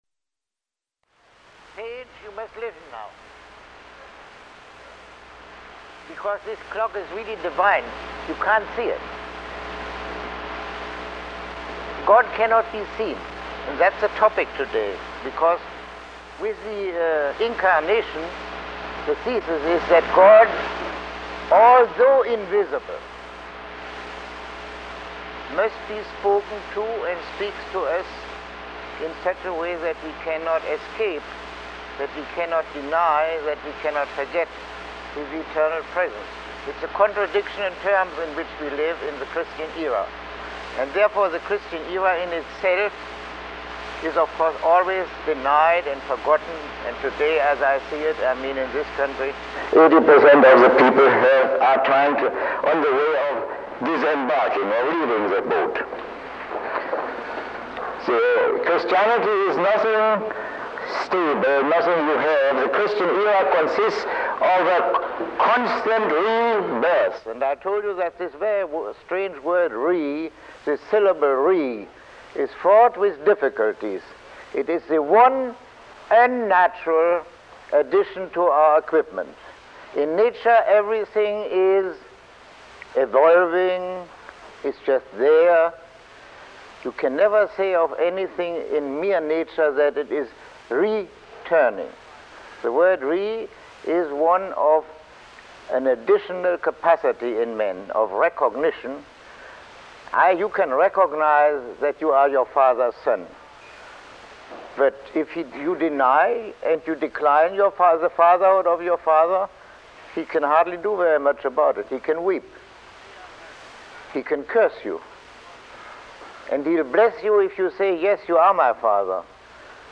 Lecture 16